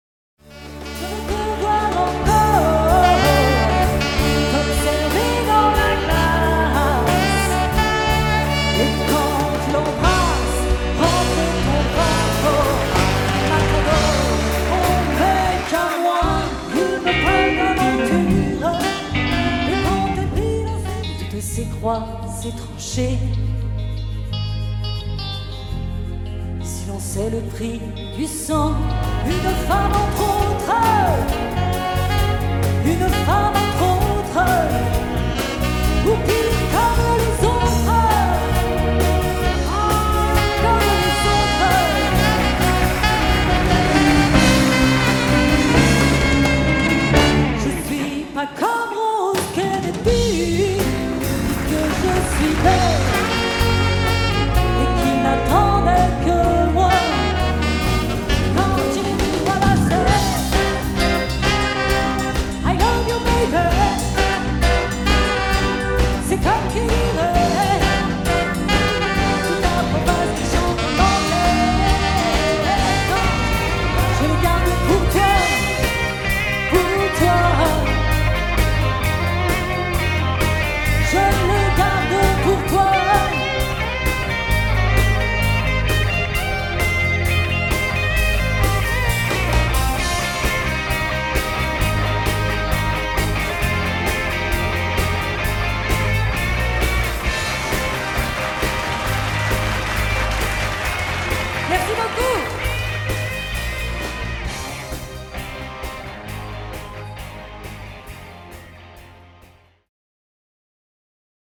(sur PBO ou en live accompagné par 5 musiciens).